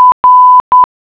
Nota.- Para escuchar el sonido, hacer clic en la clave morse correspondiente (el sonido se escuchará a una velocidad de 10 palabras/minuto).